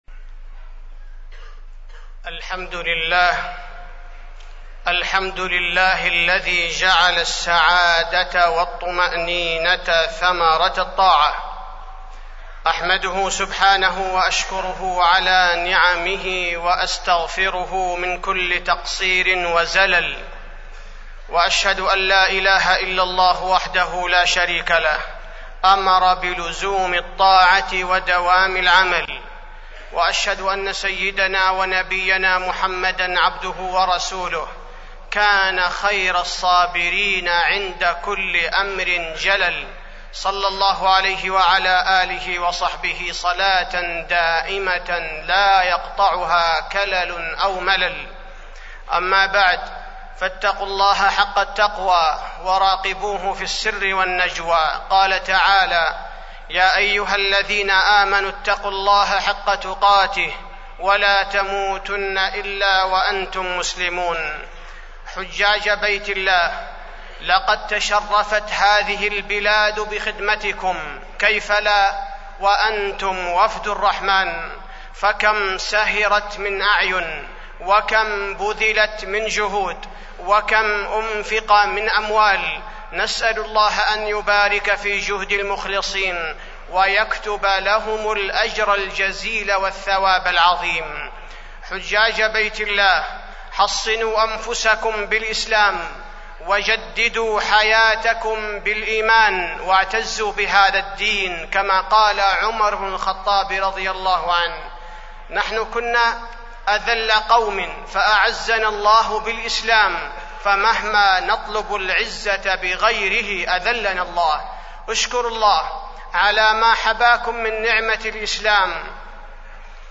تاريخ النشر ٢٠ ذو الحجة ١٤٢٦ هـ المكان: المسجد النبوي الشيخ: فضيلة الشيخ عبدالباري الثبيتي فضيلة الشيخ عبدالباري الثبيتي نصيحة للحجاج